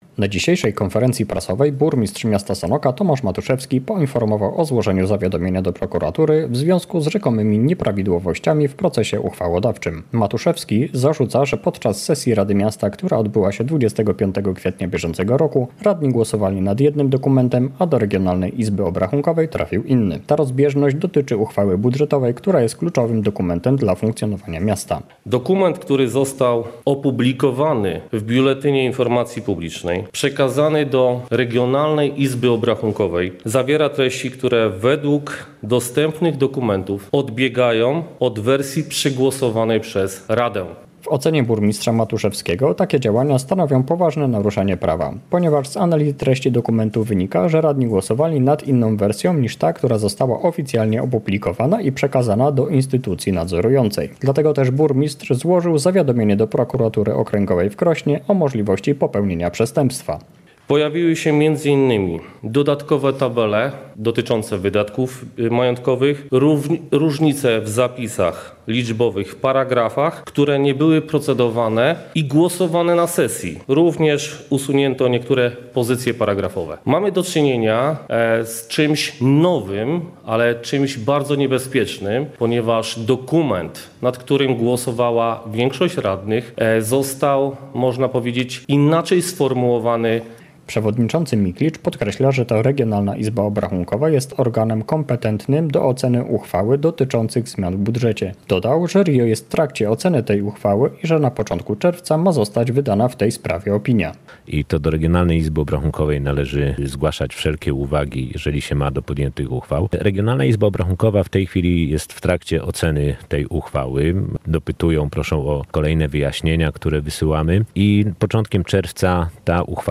Na zwołanej konferencji prasowej burmistrz stwierdził, że już po głosowaniu treść dokumentu została zmieniona i obarcza za to odpowiedzialnością przewodniczącego rady.